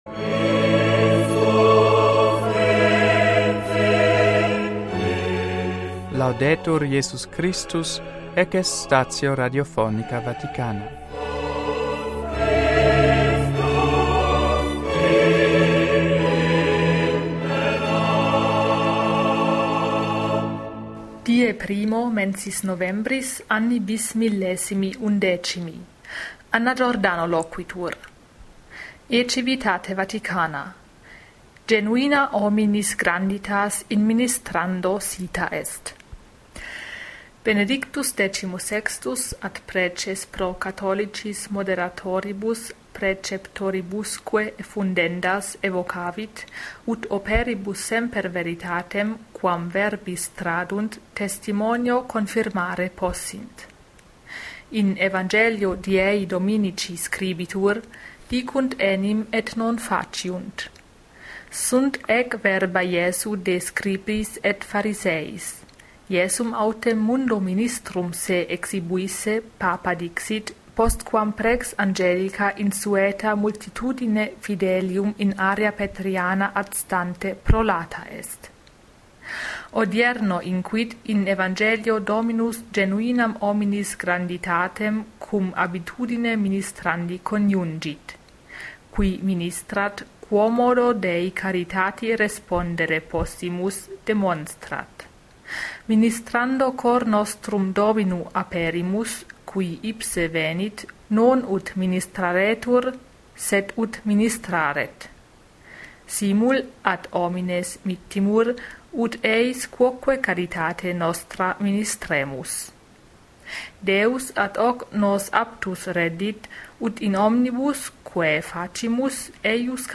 MP3 NUNTII STATIONIS RADIOPHONICAE VATICANAE PARTITIONIS GERMANICAE